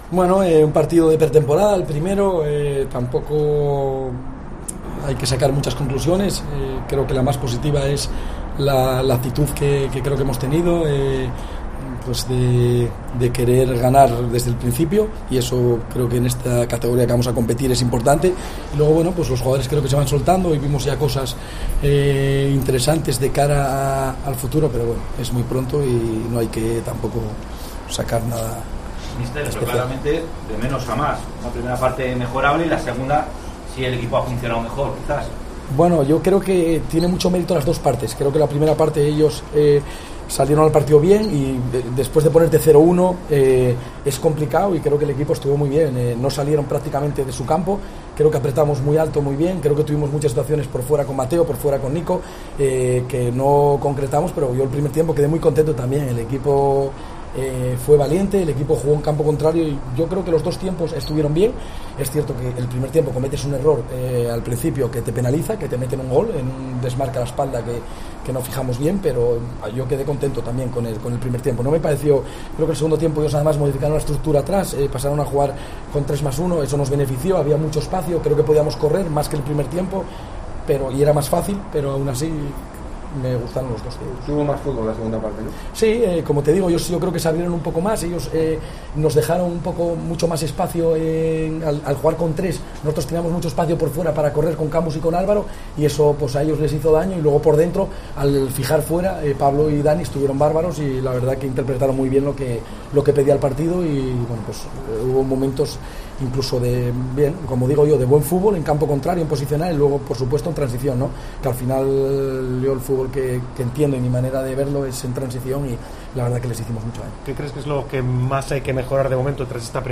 Escucha al técnico del Racing, Javi Rozada, tras remontar y ganar 2-1 al Sanse en el amistoso de pretemporada
Puedes escuchar la rueda de prensa de Javier Rozada al término del partido pinchando debajo de la fotografía.